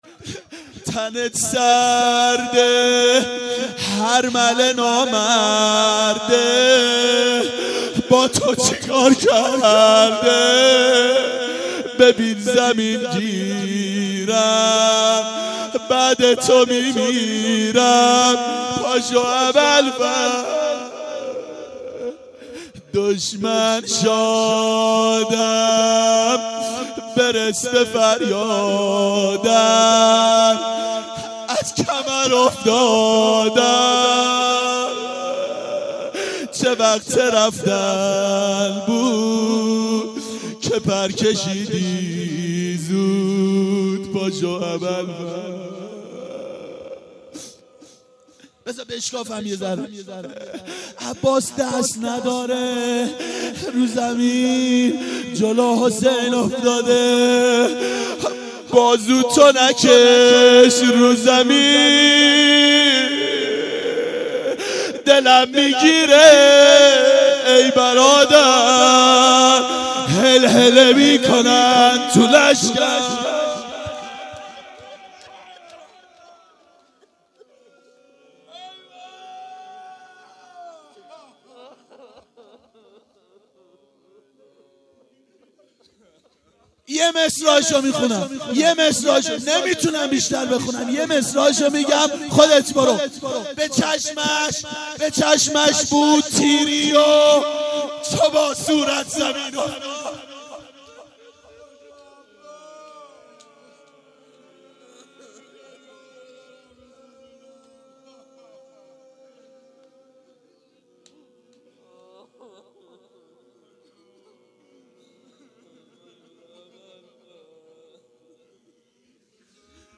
روضه شب عاشورا
شب عاشورا 1391 هیئت عاشقان اباالفضل علیه السلام
01-روضه-شب-عاشورا.mp3